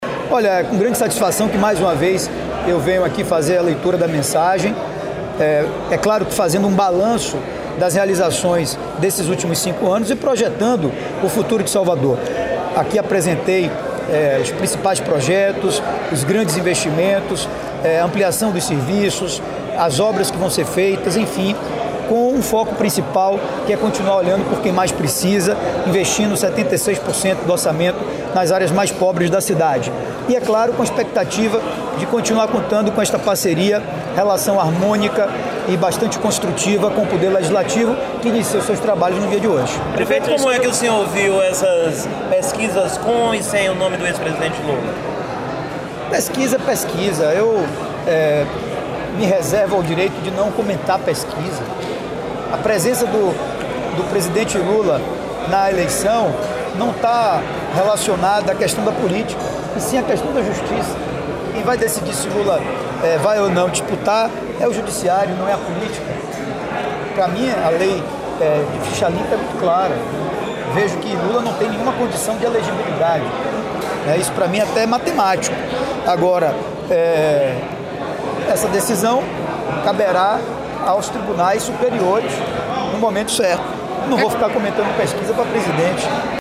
Áudio com entrevista do prefeito ACM Neto após leitura da mensagem na abertura dos trabalhos na Câmara, hoje pela manhã.